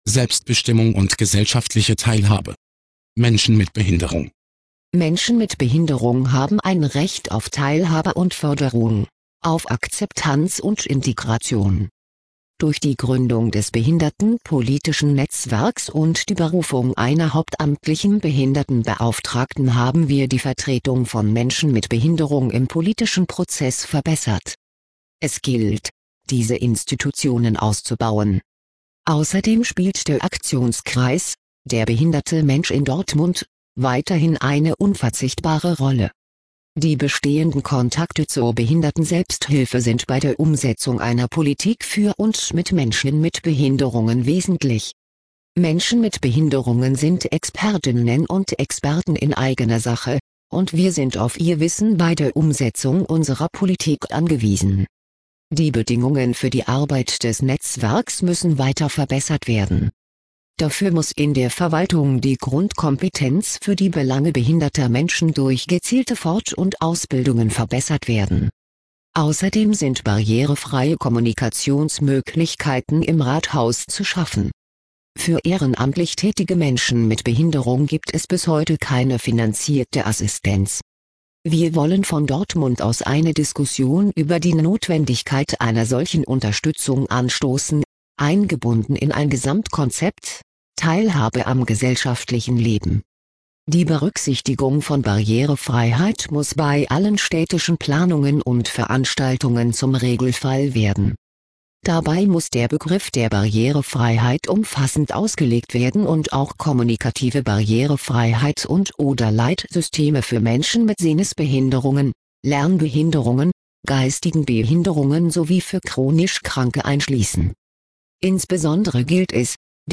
Auszüge aus unserem Kommunalwahlprogramm 2009 als Sprachversion